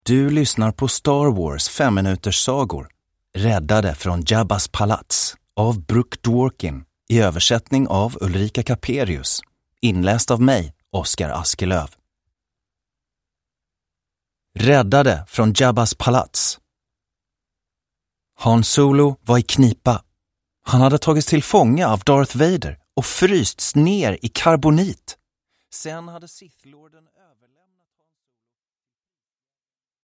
Räddade från Jabbas palats. Åttonde berättelsen ur Star Wars 5-minuterssagor – Ljudbok – Laddas ner